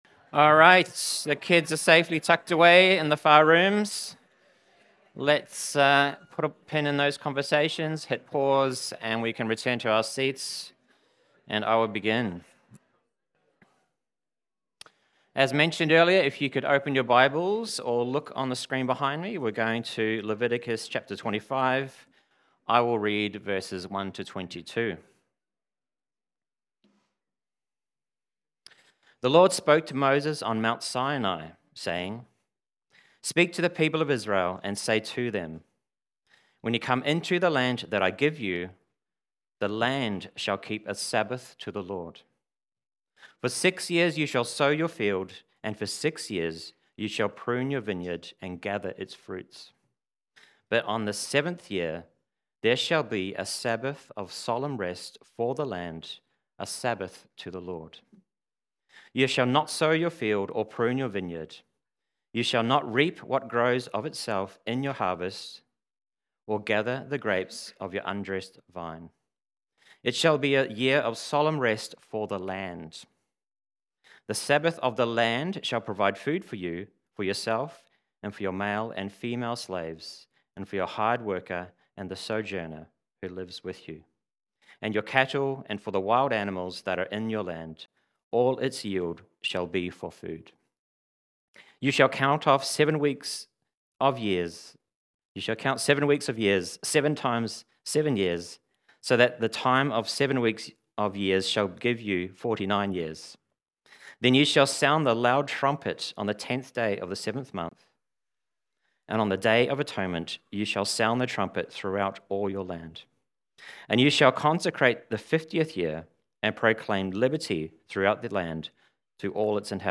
Church-Sermon-070925.mp3